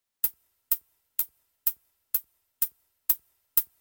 Хай-хэт — один из ключевых элементов ударной установки, создающий яркие и ритмичные звуки.
звук легкого удара по хай-хэту